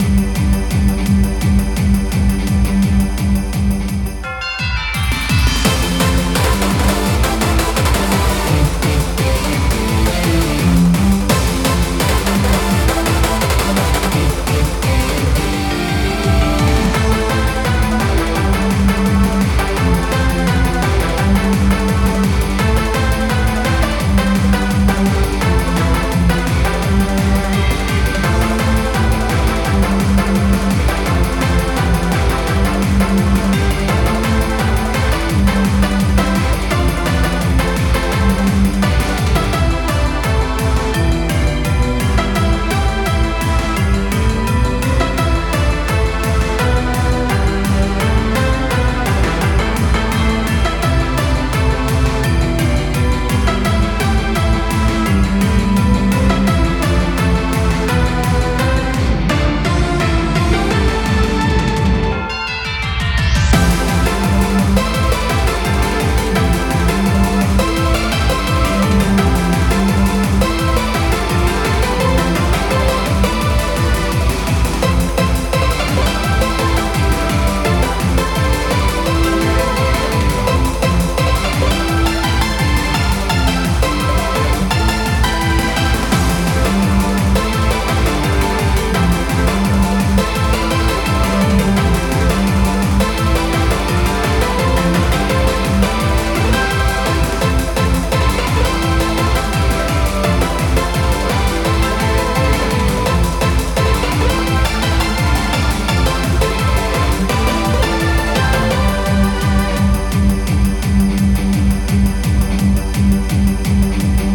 This music is loopable.
super_santa_claus_bpm170_0.ogg